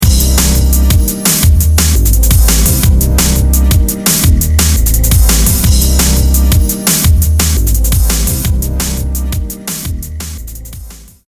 Smooth drum n bass